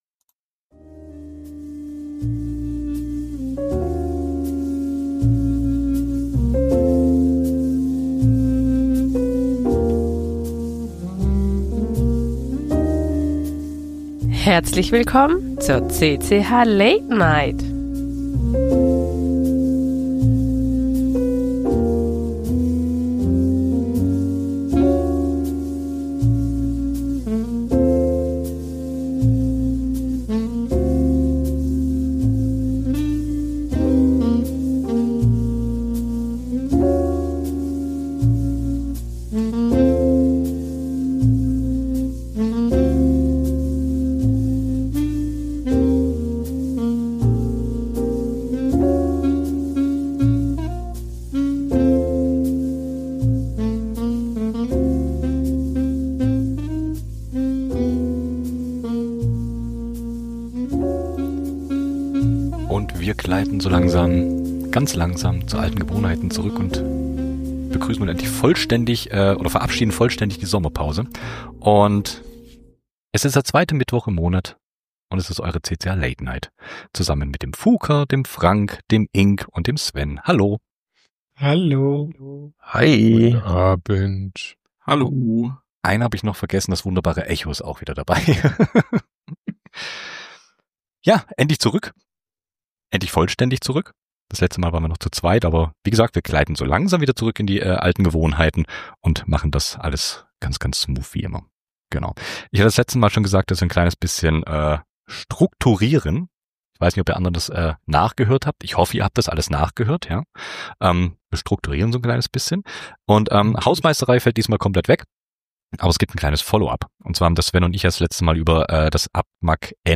Die CCH! Late Night! ist eure monatliche Live-Keyboard-Late-Night-Show.